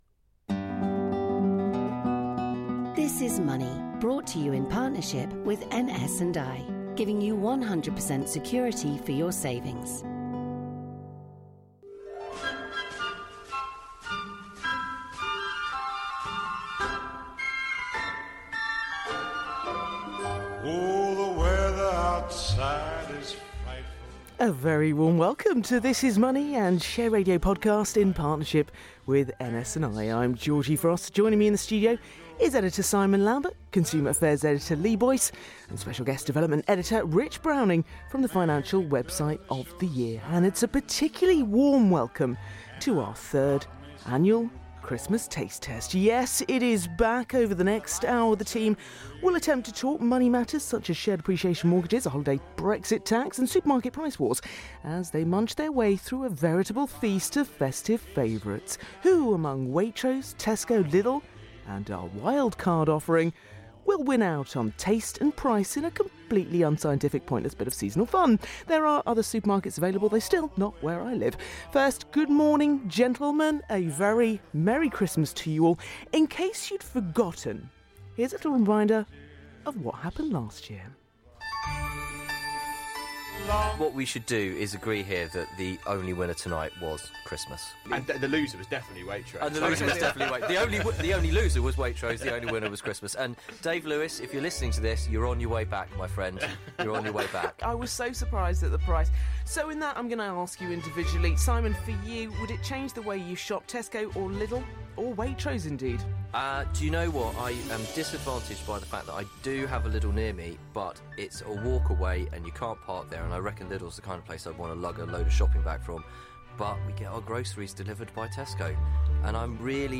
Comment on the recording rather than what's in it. It might be the only time in history that four five-course supermarket Christmas dinners are taste-tested on live radio over a discussion about the state of the economy.